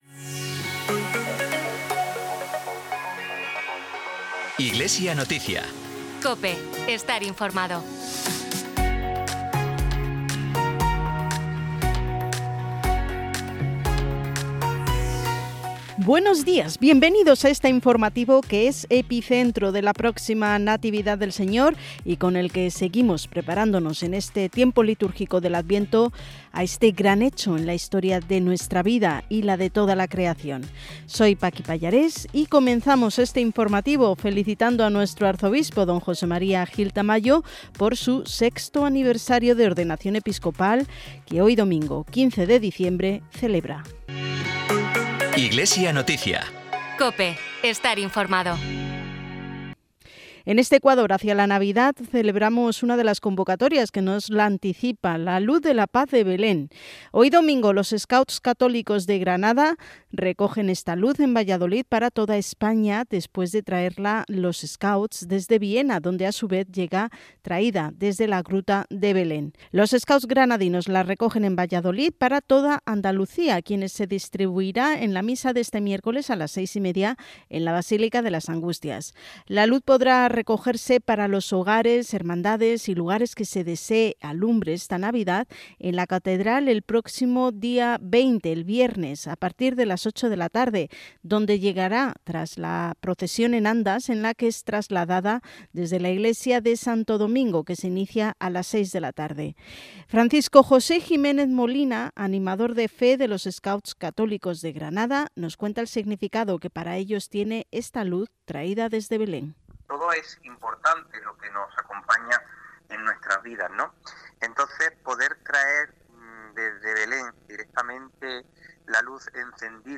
Programa emitido en COPE Granada y COPE Motril el 15 de diciembre de 2024.